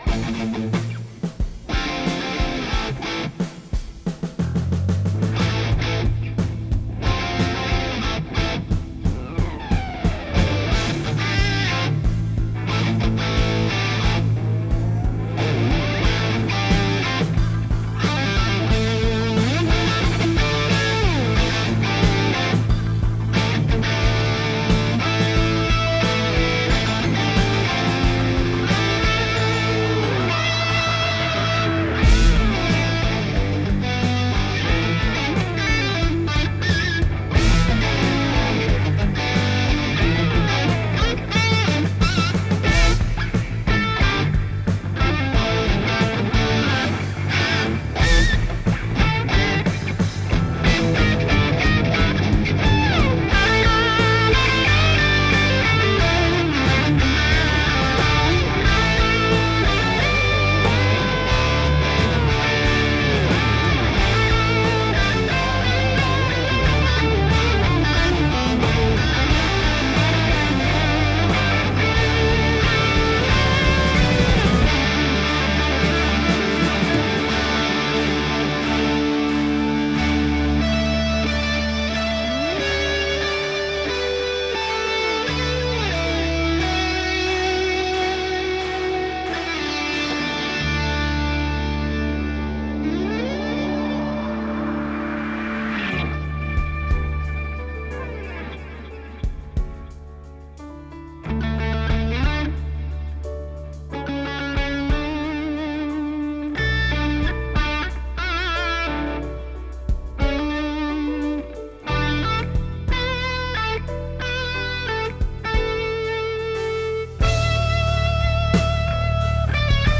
Meaning I would get two completely different sounding end results IF my idea of inspiration and “feel” for the media is in fact true.
I think it’s clear which is digital, but I think the character of both is totally different and I wonder which one has your preference.
Tried to get the levels as close as possible.